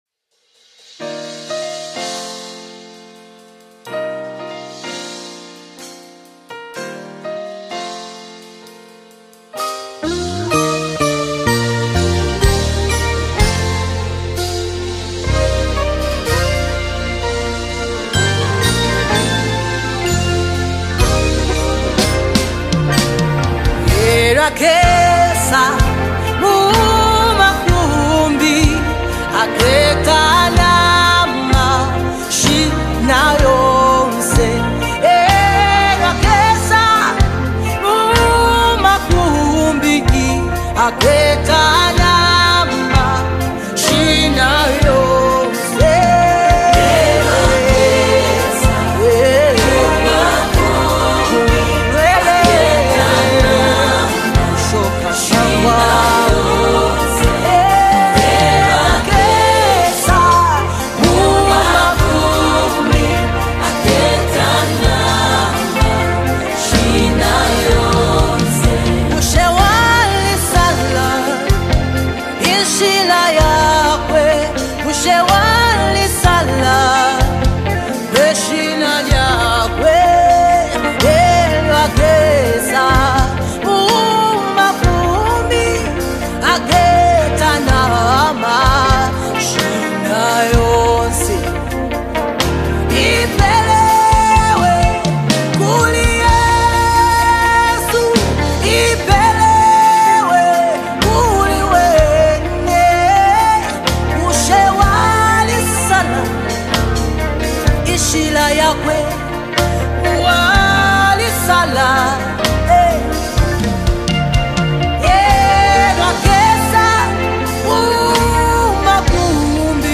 Zambian gospel